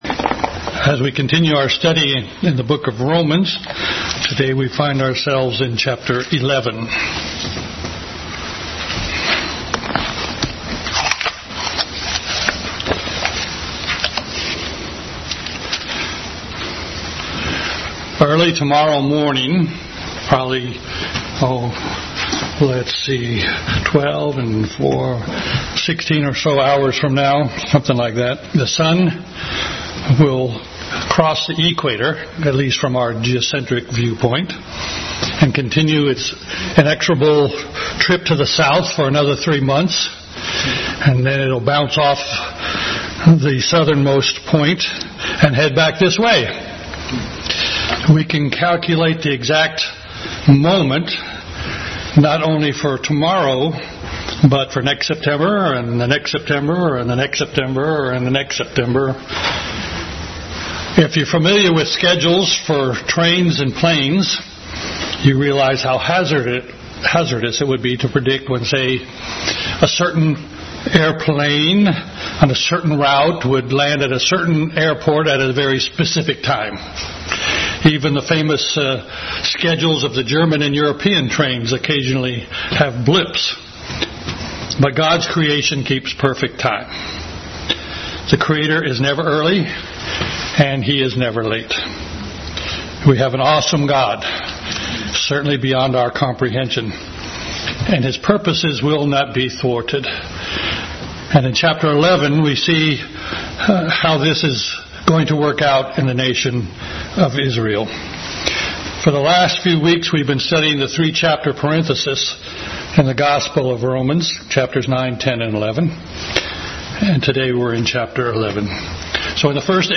Bible Text: Romans 11:1-36, Ezekiel 20,1 Kings 19 | Adult Sunday School class. Continued study in the book of Romans.